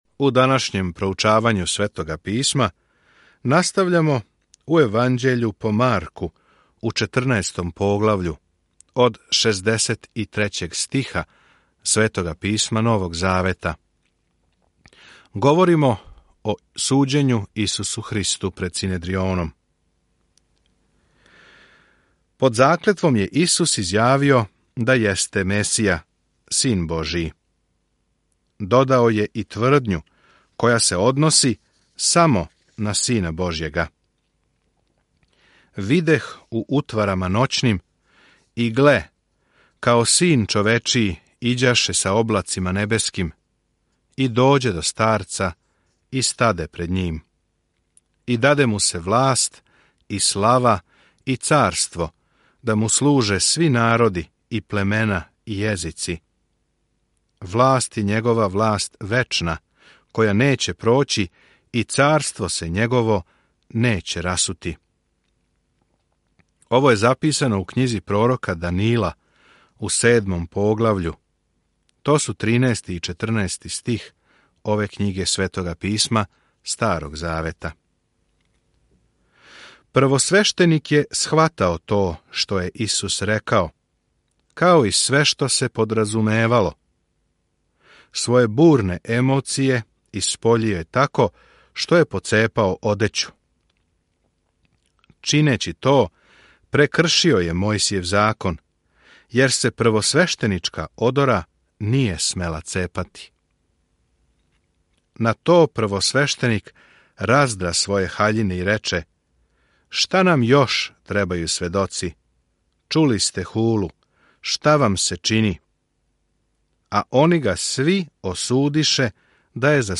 Sveto Pismo Marko 14:63-72 Marko 15:1-13 Dan 22 Započni ovaj plan Dan 24 O ovom planu Марково краће јеванђеље описује земаљску службу Исуса Христа као напаћеног Слуге и Сина човечијег. Свакодневно путујте кроз Марка док слушате аудио студију и читате одабране стихове из Божје речи.